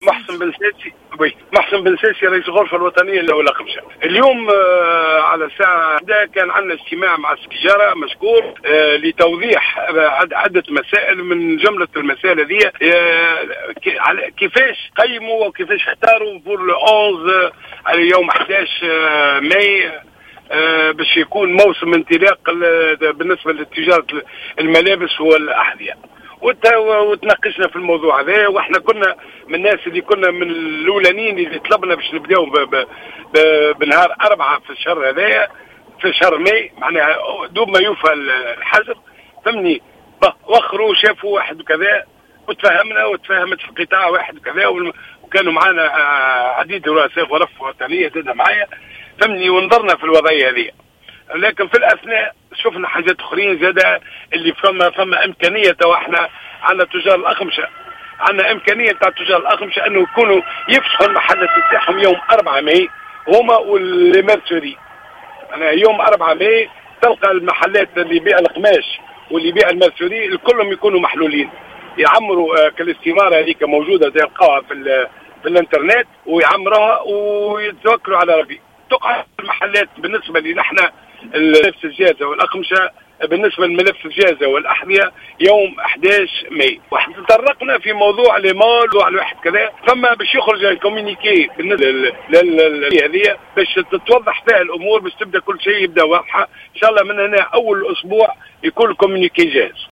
في اتصال هاتفي بالجوهرة أف -أم